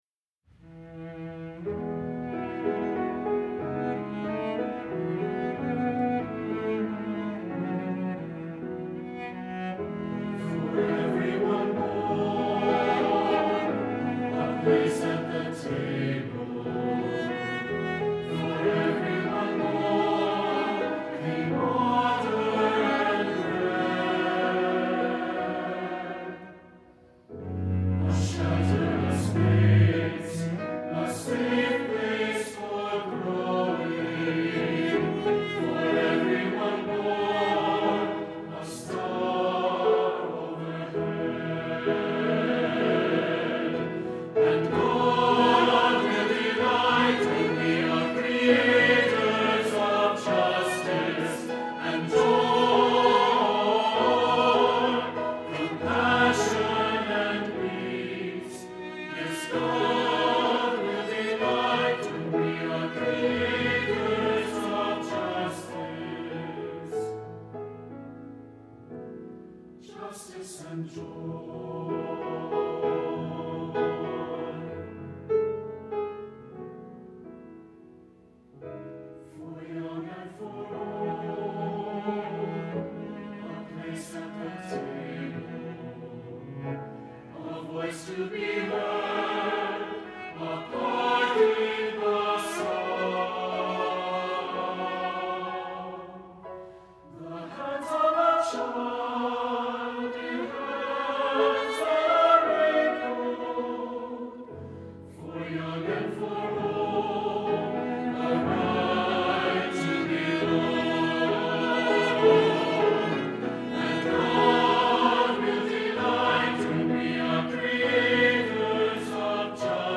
Voicing: Unison Voices, Cello, and Piano, with opt. Descant